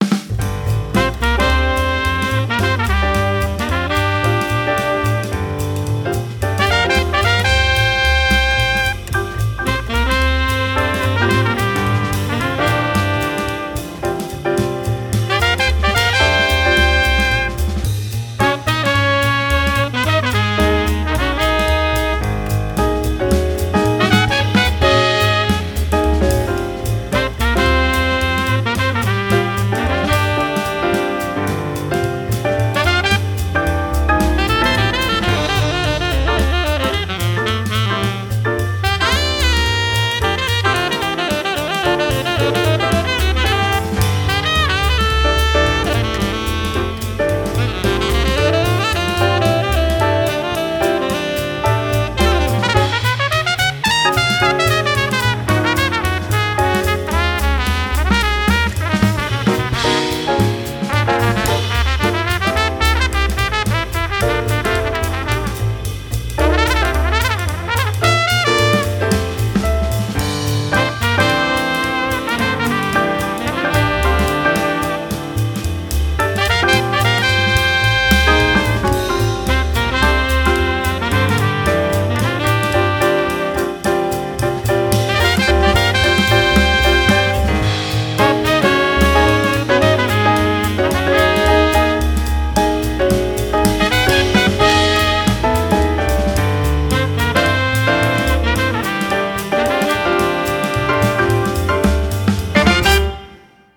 ModalJazz.mp3